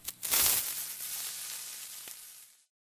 glass.ogg